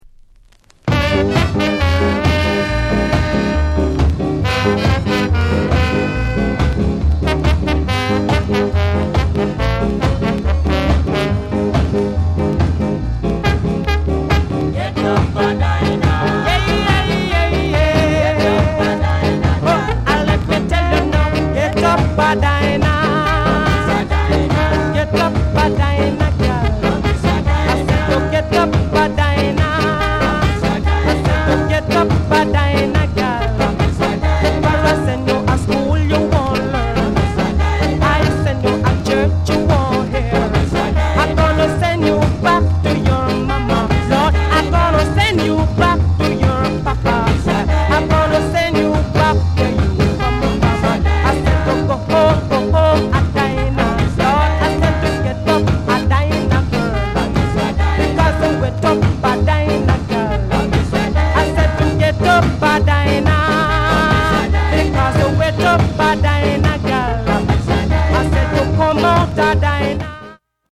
NICE SKA